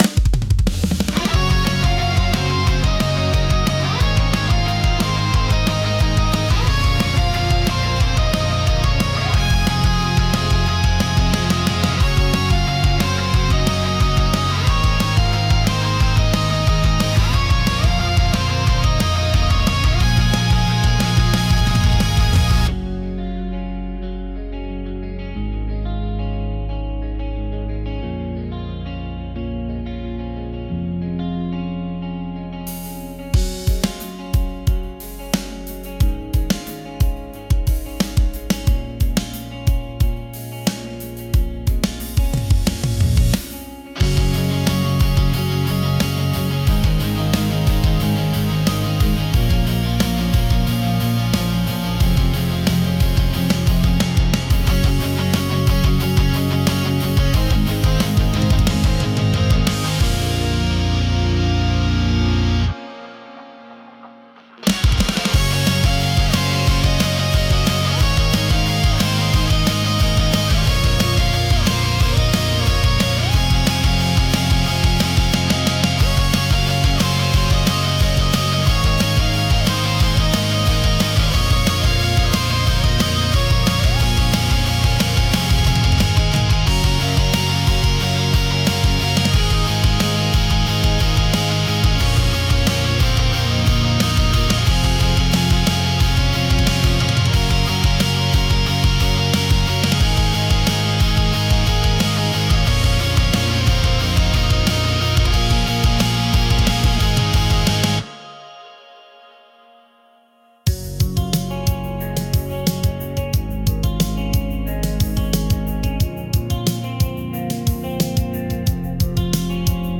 公開している音楽は、映画やアニメ、ゲームに想定したBGMや、作業用のBGMを意識して制作しています。